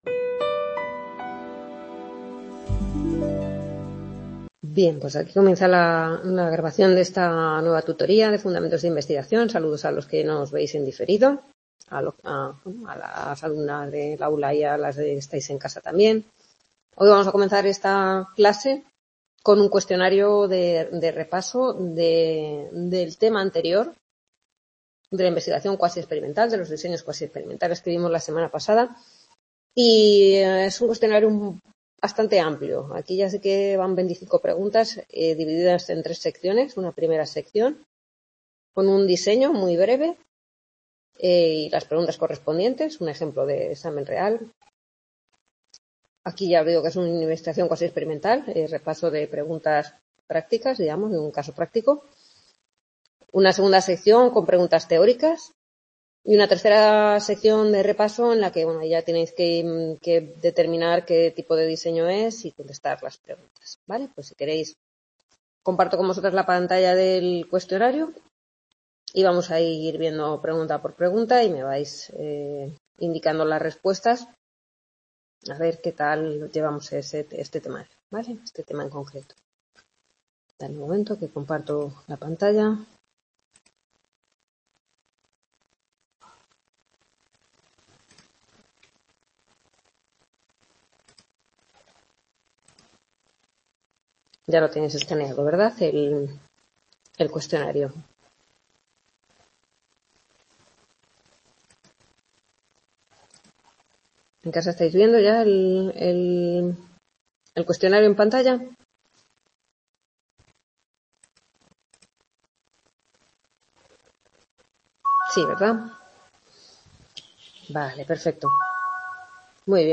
Grabación de la octava tutoría de la asignatura Fundamentos de investigación del Grado en Psicología impartida en el C.A. Rivas (UNED, Madrid). Corresponde a la actividad práctica del capítulo 6 de la asignatura, Investigación cuasiexperimental, y explicación del capítulo 7, Diseños ex post facto.